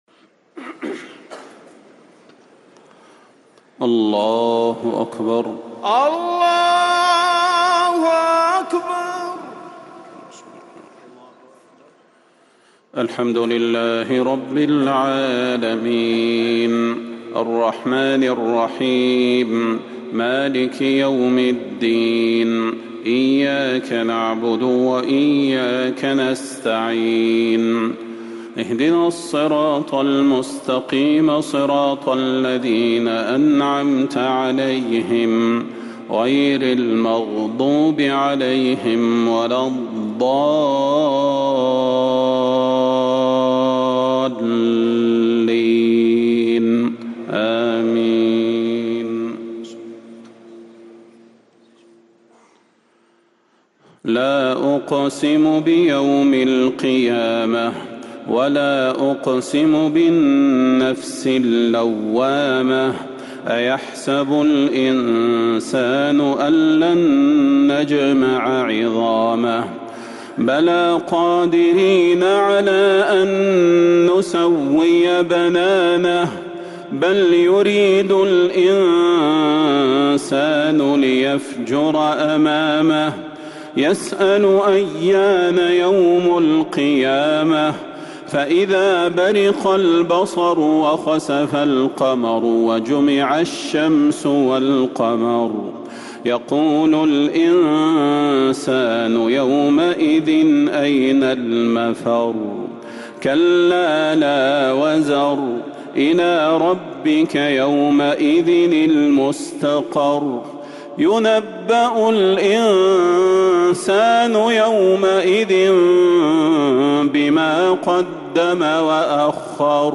تهجد ليلة 28 رمضان 1444هـ من سورة القيامة حتى سورة النازعات | Tahajjud 28th night of Ramadan 1444H Surah Al-Qiyaama to An-Naziat > تراويح الحرم النبوي عام 1444 🕌 > التراويح - تلاوات الحرمين